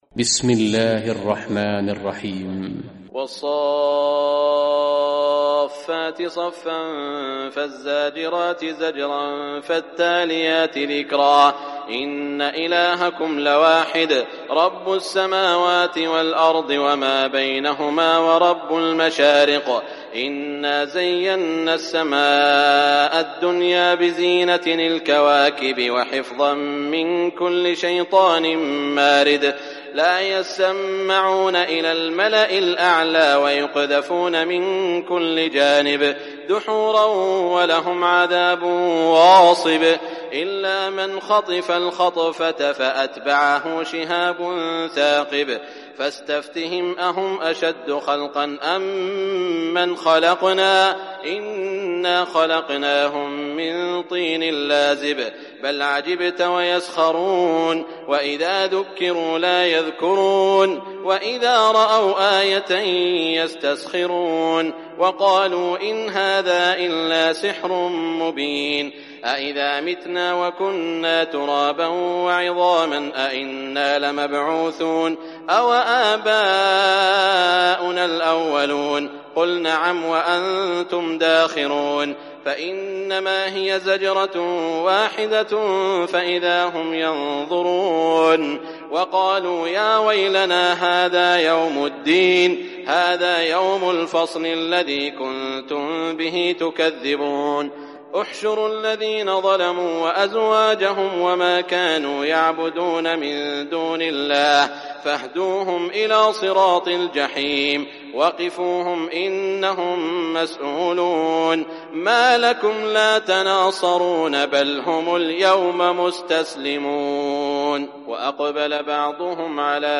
Surah As-Saffat Recitation by Sheikh Shuraim
Surah As-Saffat, listen or play online mp3 tilawat / recitation in Arabic in the beautiful voice of Sheikh Saud al Shuraim.